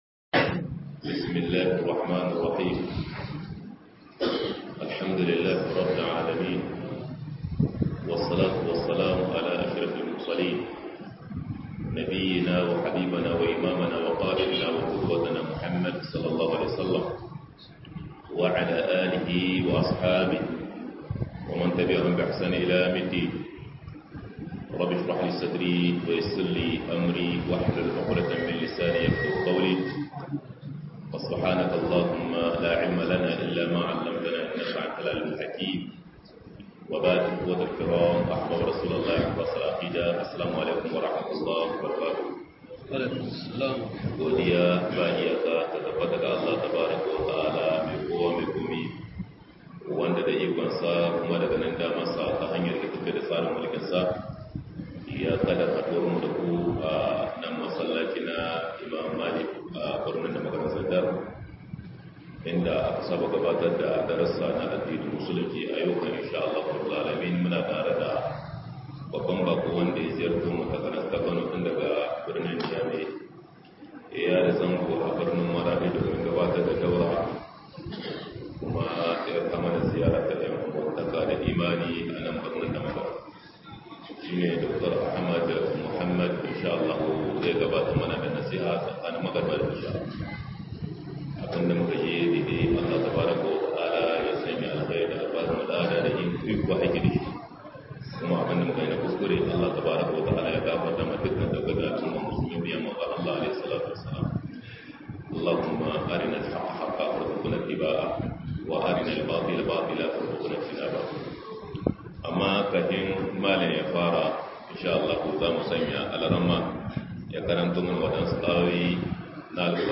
نصيحة في إصلاح البيوت - MUHADARA